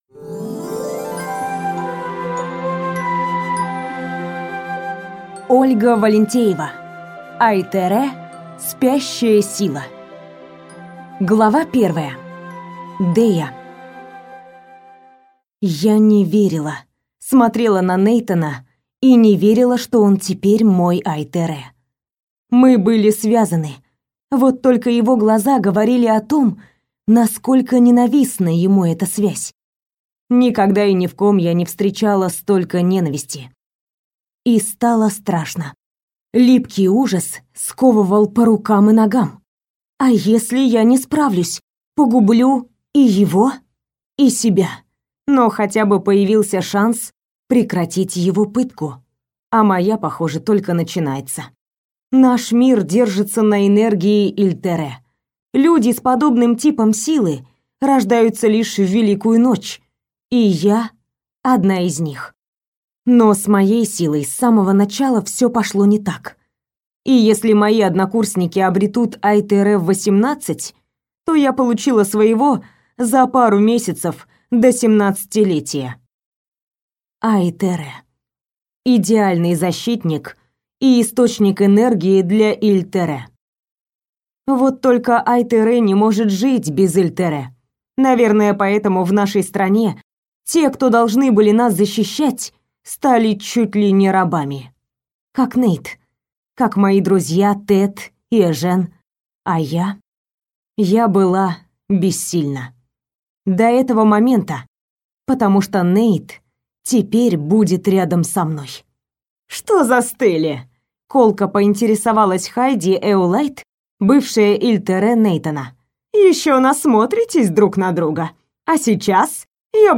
Аудиокнига Ай-тере. Спящая сила | Библиотека аудиокниг